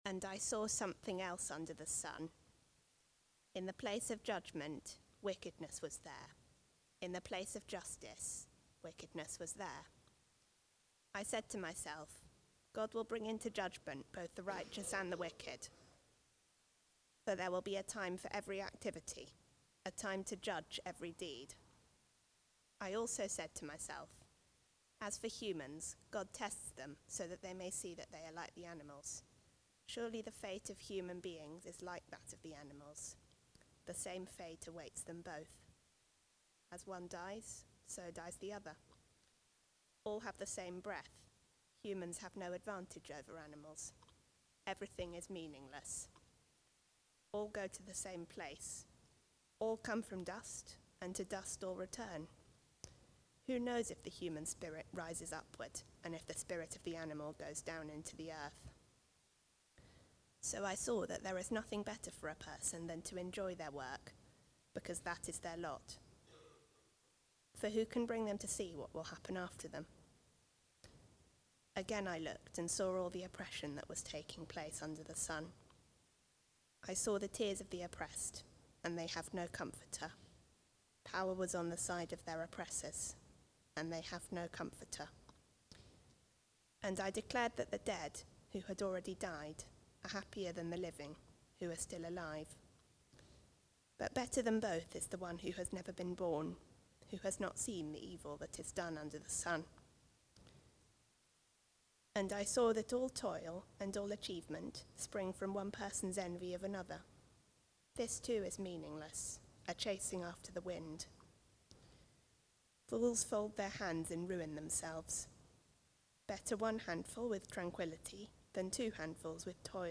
Preaching
What is Better (Ecclesiastes 3:16-4:16) from the series Chasing After the Wind. Recorded at Woodstock Road Baptist Church on 28 September 2025.